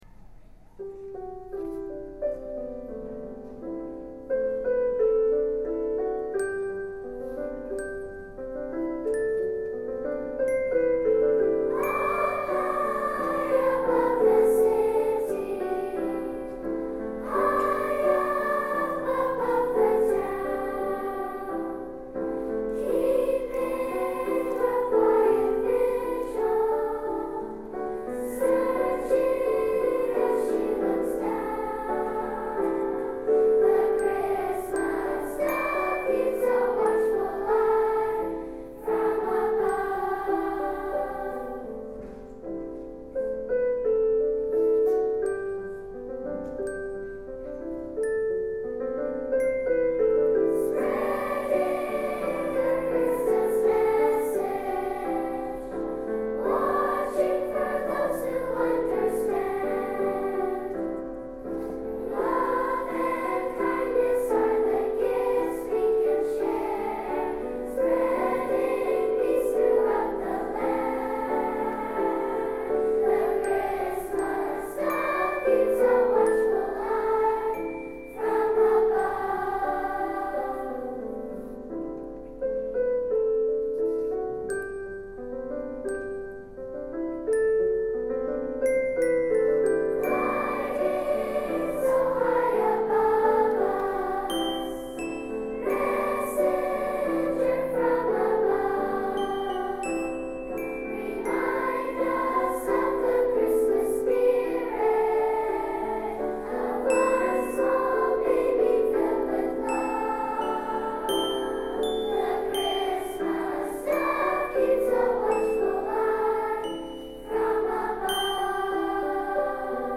Tonart(en): G-Dur